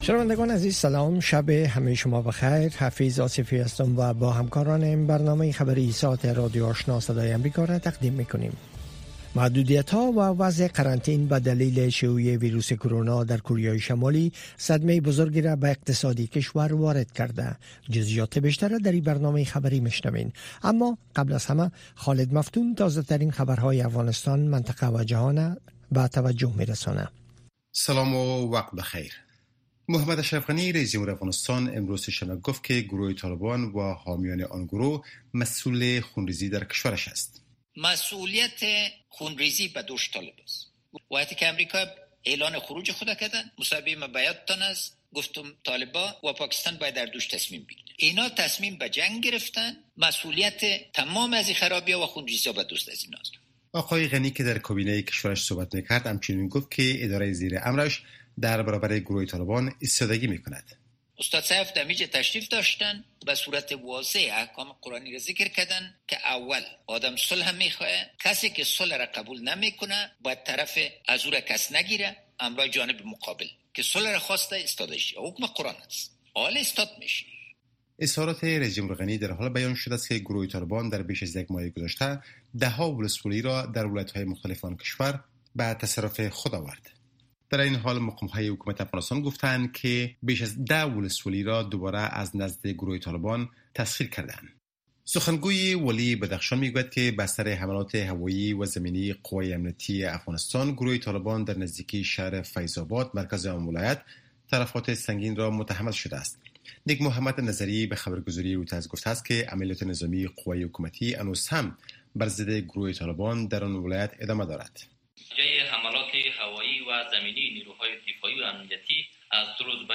دومین برنامه خبری شب